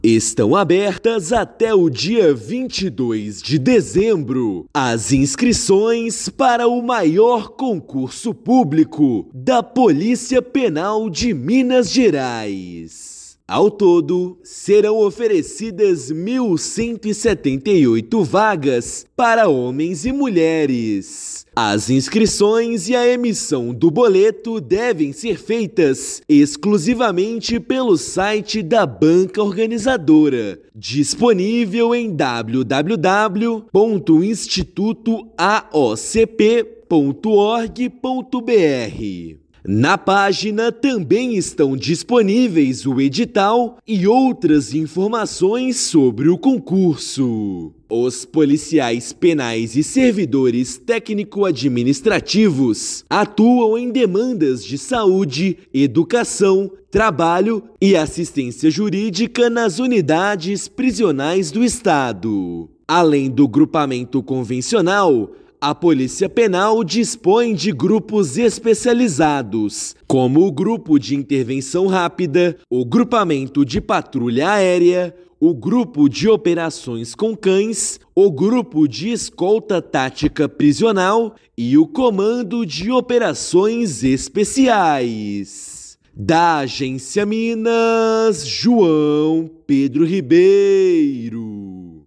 Inscrições vão até 22/12 e provas serão aplicadas em janeiro de 2026. Ouça matéria de rádio.